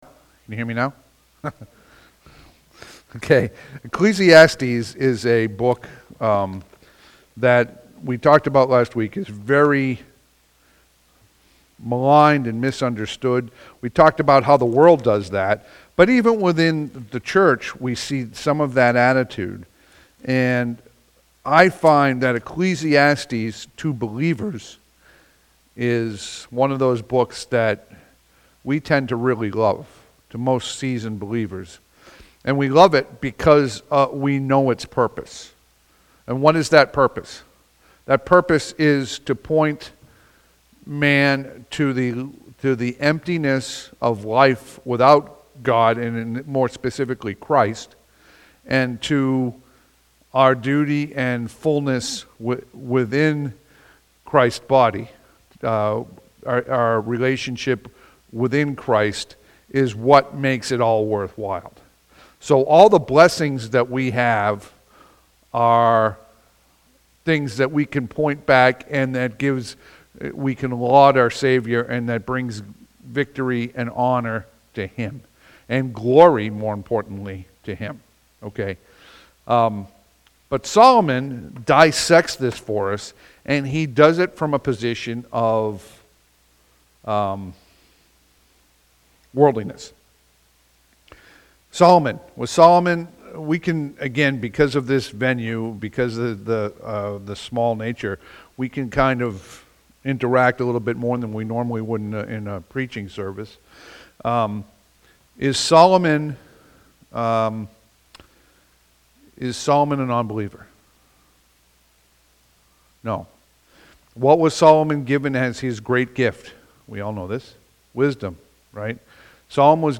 Passage: Ecclesiastes 1 Service Type: Sunday PM « May 4